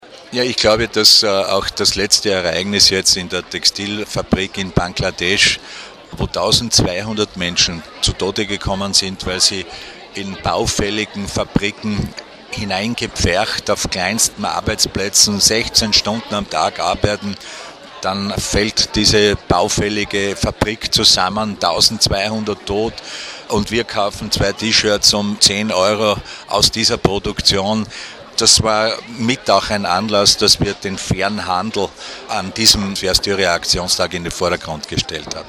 O-Ton: FairStyria-Aktionstag in Graz
Landeshauptmann Franz Voves